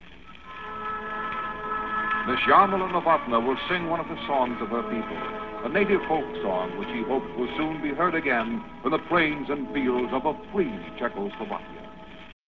I Saw My Country Die: A Radio Interview with Jarmila Novotna